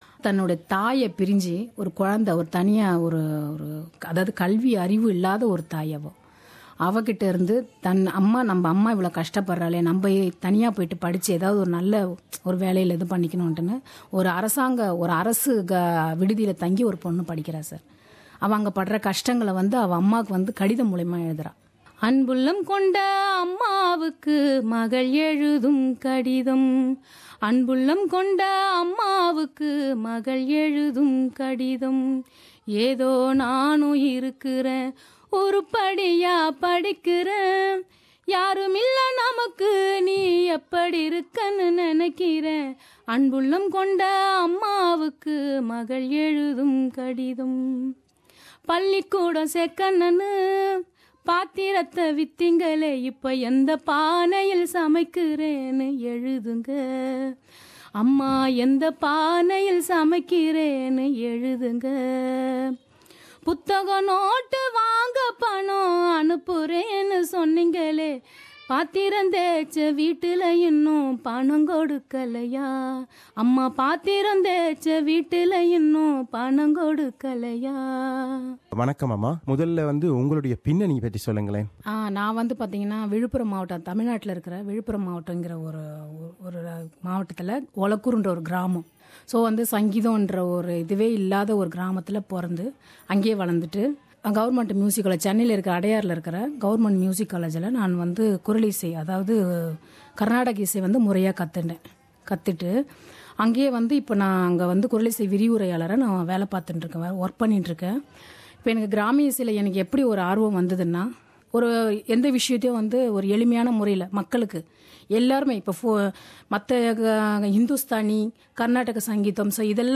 தன் இனிய குரலால் நாட்டுப் புறப் பாடல்களைப் பாடியும் நம்மை வியக்க வைக்கிறார்